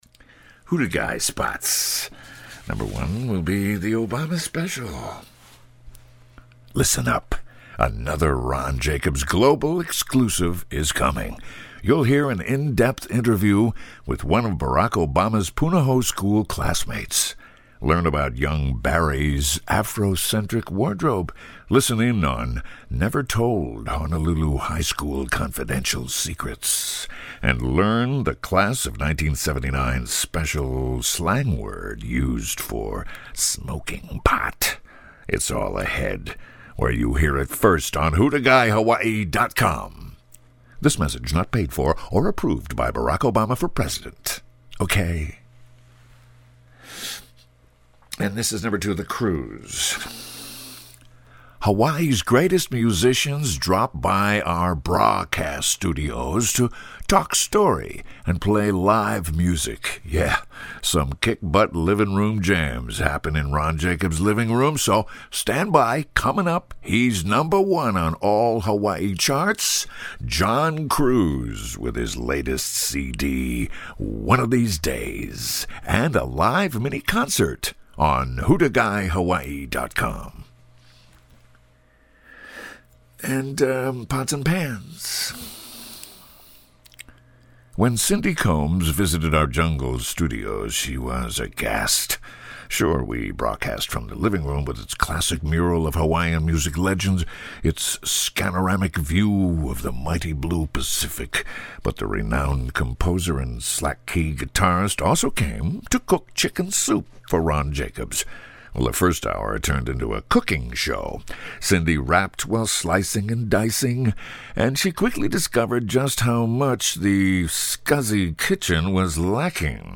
You wanna hear the perfect read and THE voice?